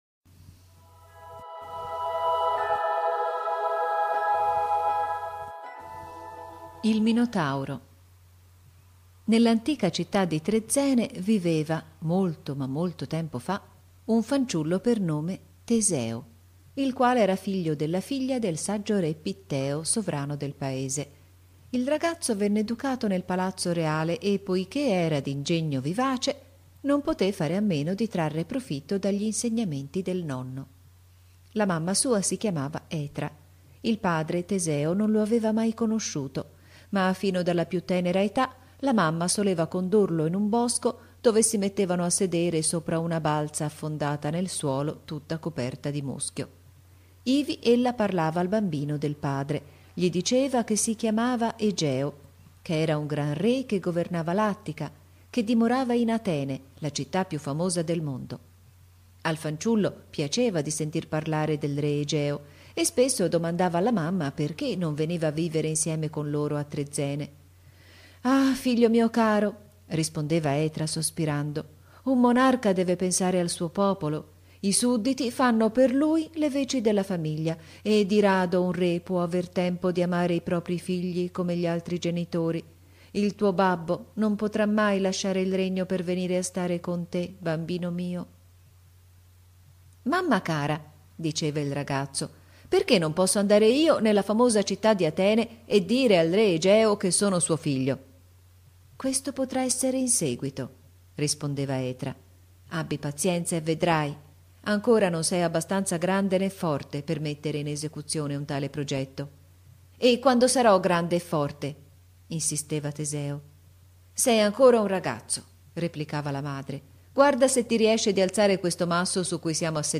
Qui potete riascoltarla, raccontata certo in modo diverso, ma pur sempre lei.